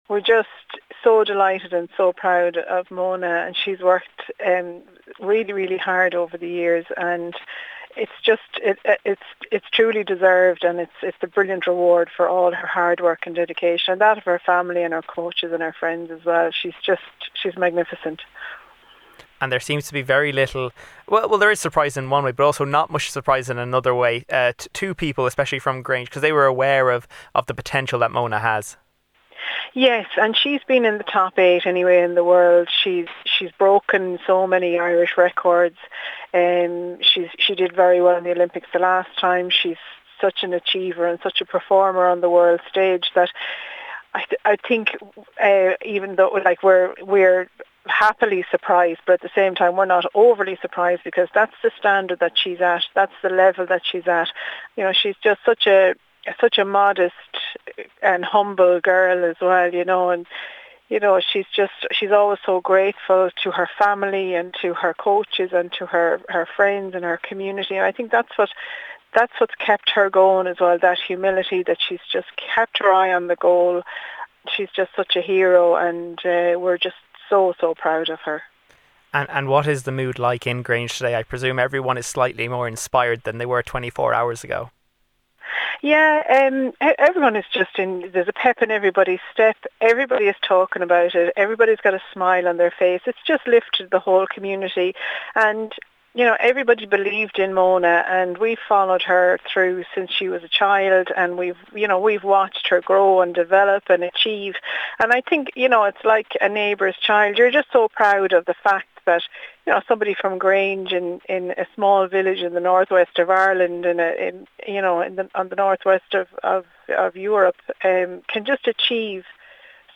Local Cllr Marie Casserly praised Ms McSharry's achievement and spoke about the mood in Grange today: